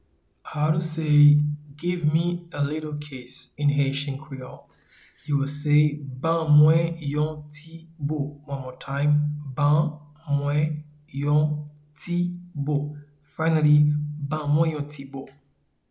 Pronunciation and Transcript:
Give-me-a-little-kiss-in-Haitian-Creole-Ban-mwen-yon-ti-bo.mp3